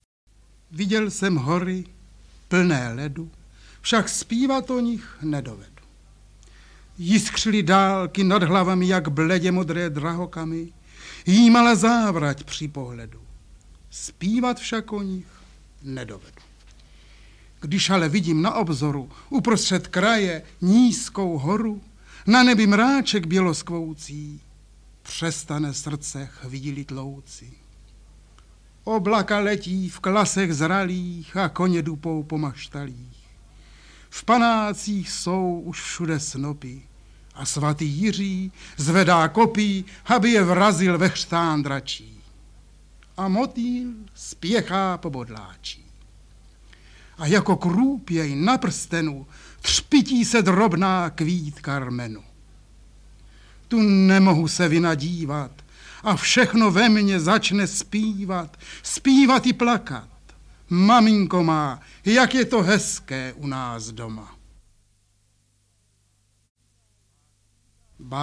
Verše z díla Jaroslava Seiferta: recituje Jaroslav Seifert a Dana Medřická
Básně Hora Říp a Básník a jiná řemesla recituje sám autor, další - Píseň rodné zemi, Jaro 1963, Květnové noci a Rozhovor nezapomenutelná Dana Medřická.